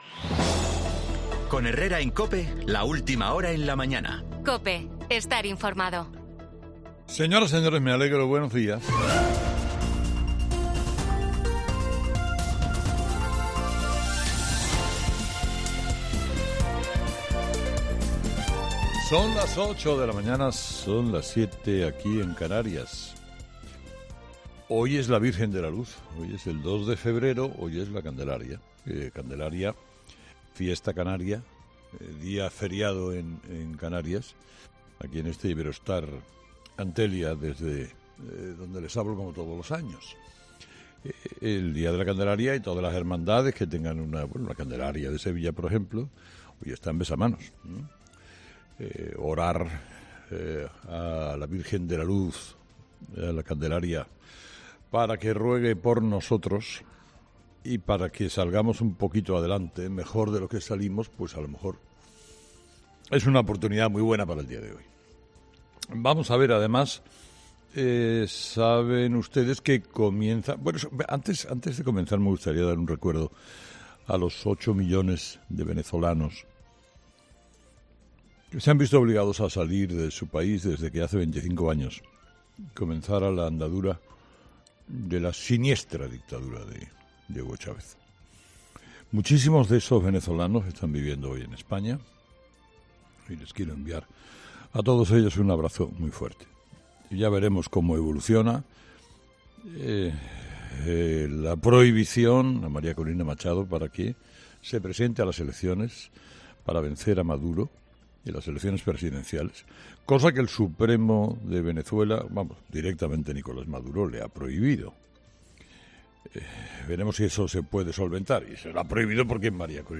Monólogo de las 8 de Herrera
Carlos Herrera, director y presentador de 'Herrera en COPE', analiza las principales claves de hoy que pasan, entre otros asuntos, por las palabras de Pedro Sánchez sobre la amnistía y los delitos de terrorismo o por la campaña de las elecciones gallegas.